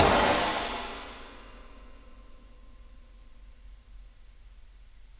RAIN RIDE.wav